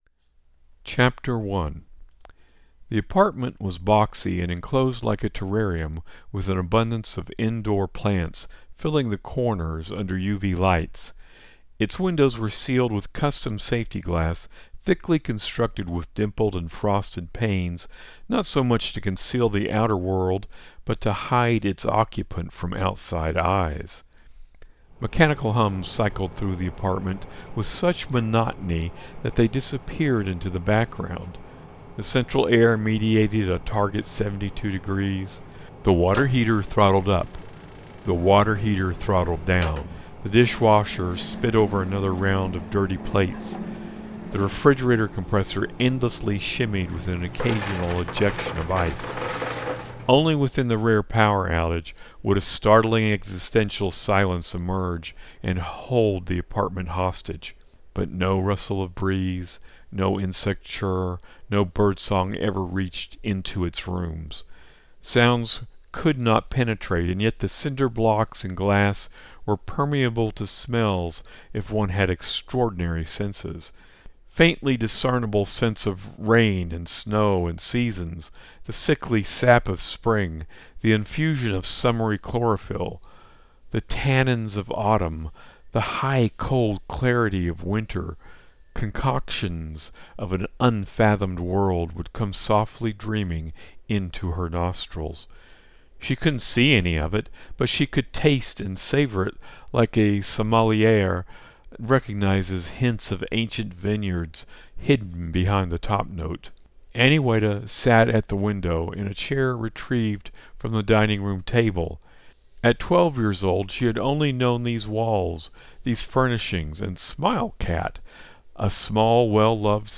Aniweta audiobook coming soon